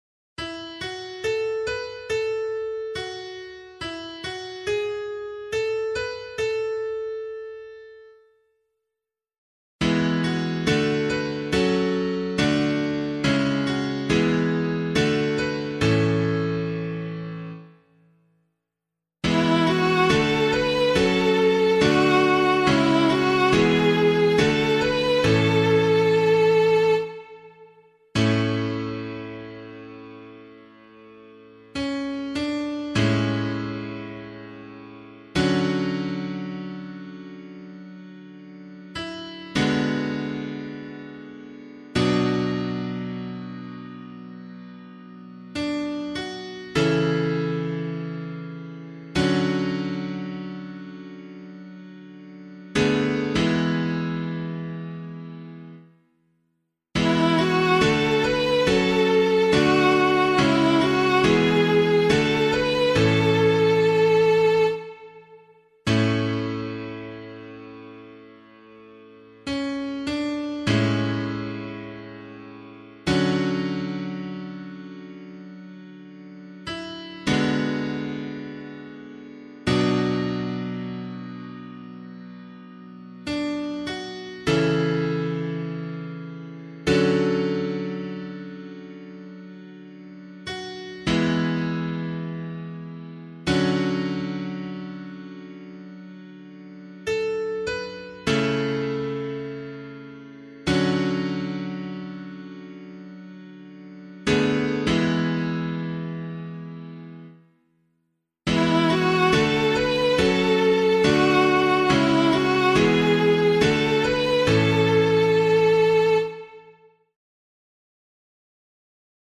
068 Christ the King Psalm C [APC - LiturgyShare + Meinrad 8] - piano.mp3